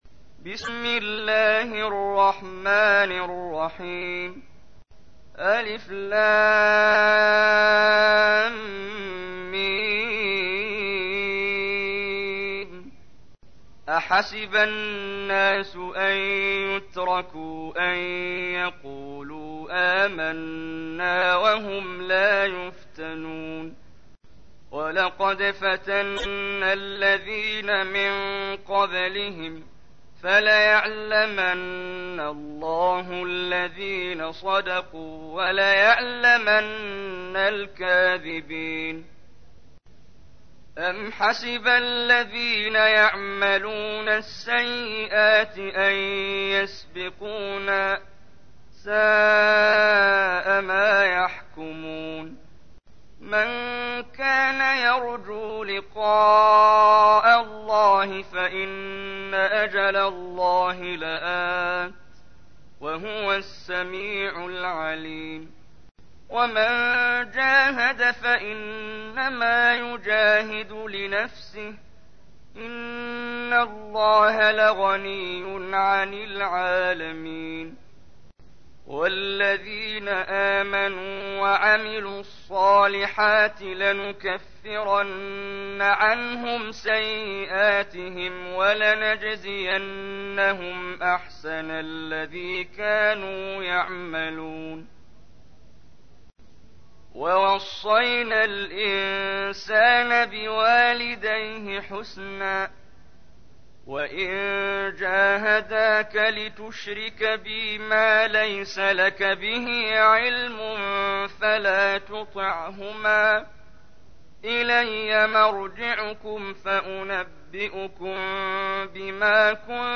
تحميل : 29. سورة العنكبوت / القارئ محمد جبريل / القرآن الكريم / موقع يا حسين